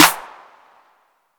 CLAP2.wav